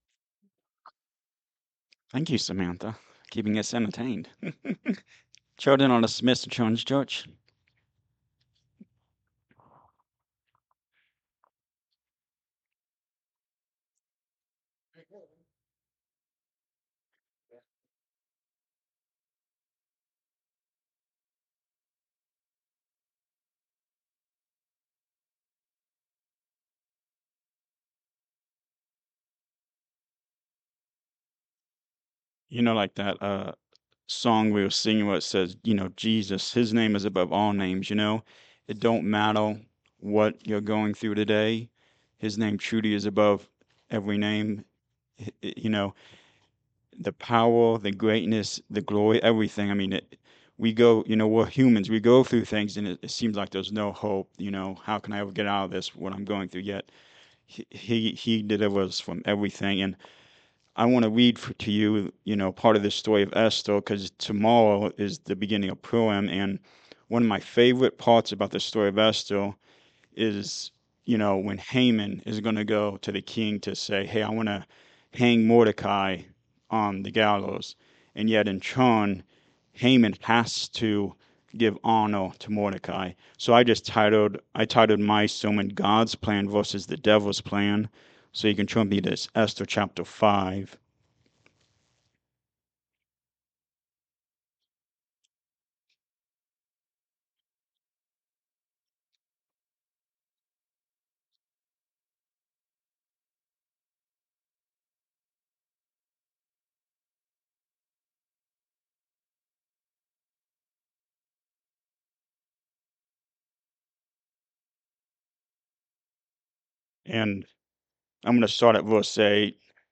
Service Type: Sunday Morning Service
Sunday-Sermon-for-March-1-2026.mp3